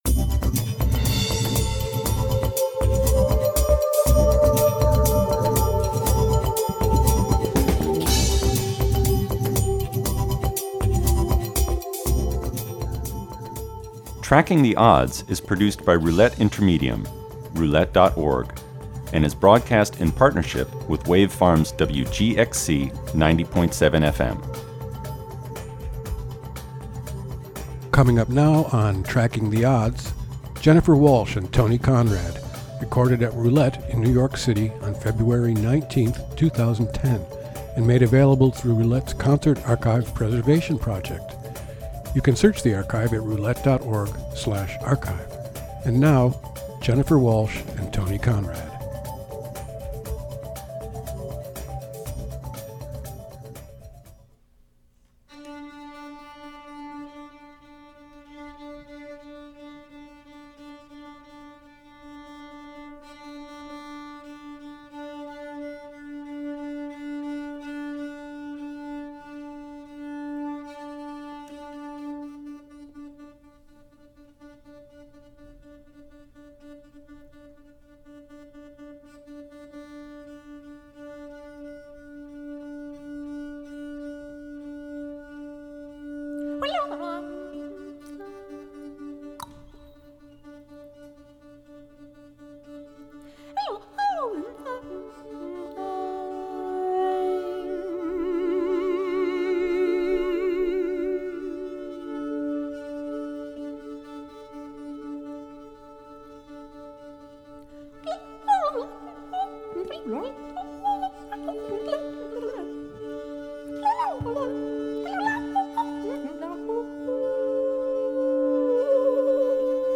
This is an excerpt from a two hour performance.